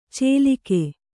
♪ cēlike